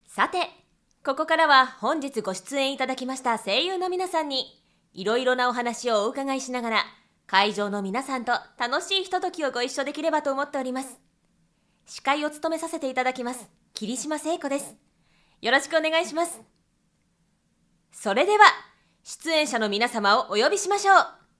ナレーター｜MC